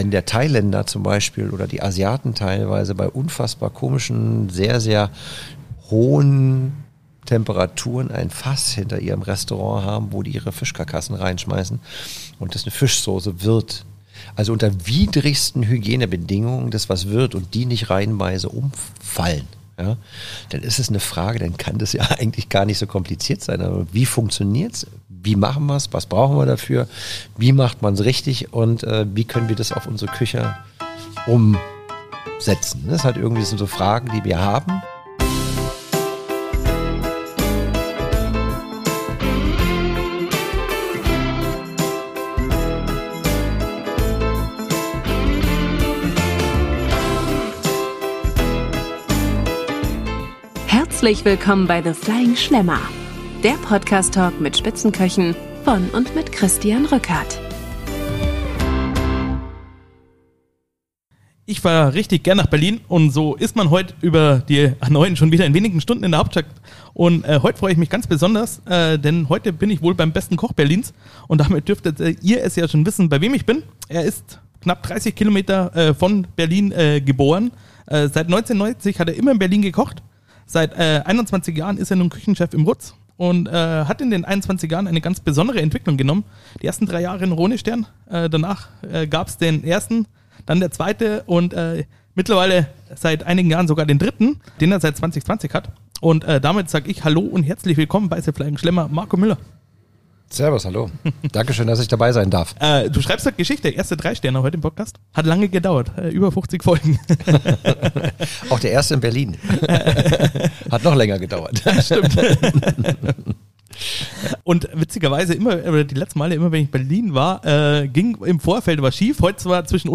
Beschreibung vor 8 Monaten Pünktlich zur 50. Folge ist es soweit: Mein erster 3-Sterne-Koch Marco Müller ist zu Gast!